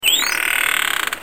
دانلود آهنگ دلفین 4 از افکت صوتی انسان و موجودات زنده
دانلود صدای دلفین 4 از ساعد نیوز با لینک مستقیم و کیفیت بالا
جلوه های صوتی